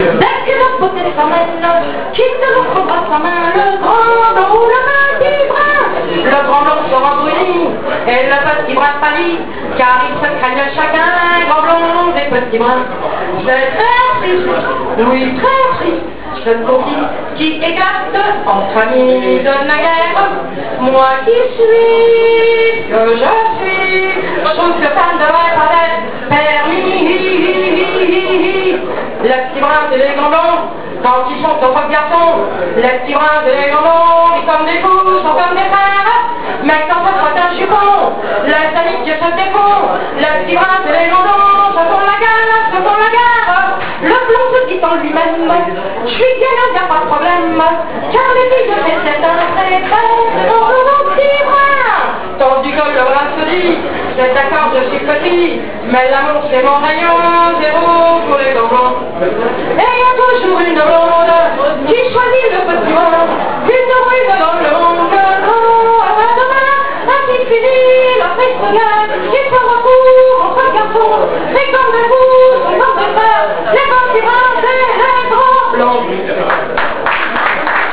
Reprises de Chansons françaises
Excellentes reprises à capéla.